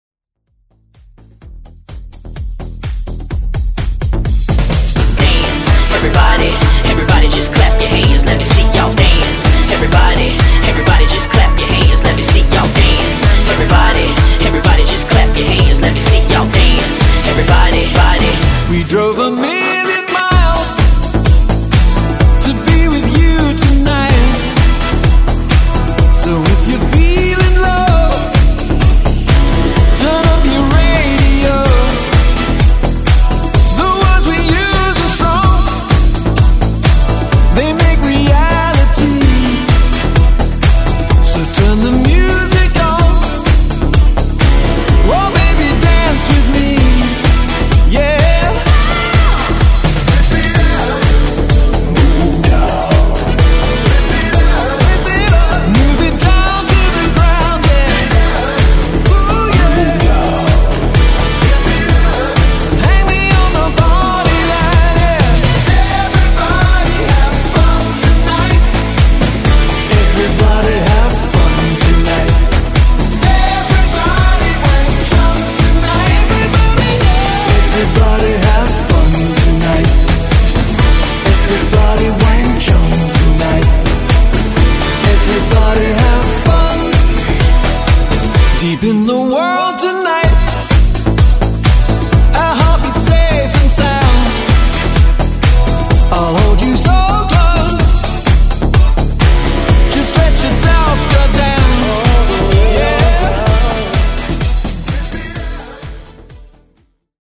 Genres: FUTURE HOUSE , MASHUPS , TOP40
Clean BPM: 128 Time